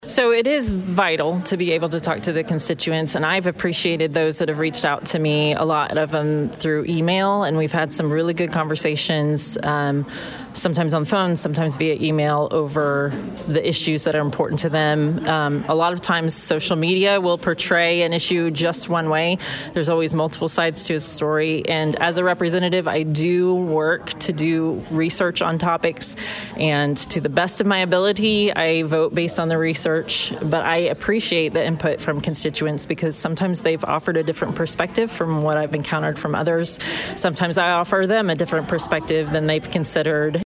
Leadington, Mo. (KFMO) - State Representative Becky Laubinger of Missouri’s 117th District spoke at Tuesday’s Park Hills–Leadington Chamber of Commerce luncheon.